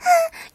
moan4.ogg